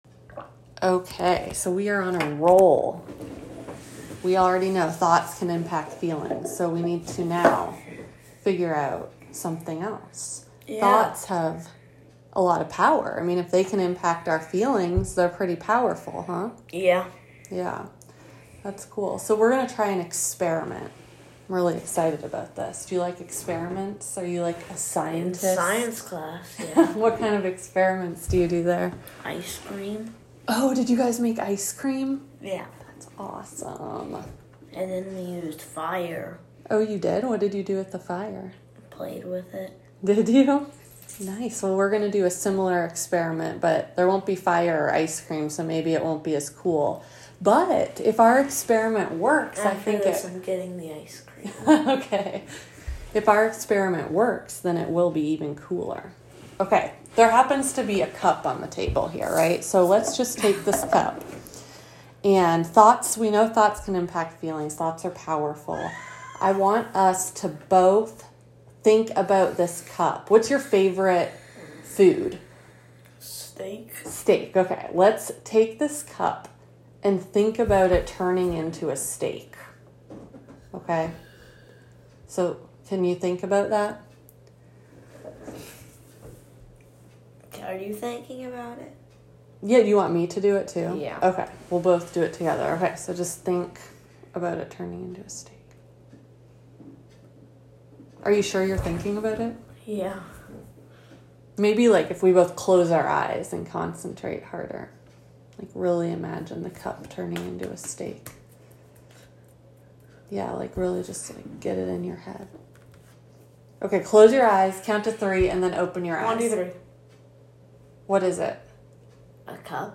Below you will find audio recordings of sample techniques for various Exposure-Based CBT sessions with kids.
Child Therapist Demo: Not All Thoughts Are True or Helpful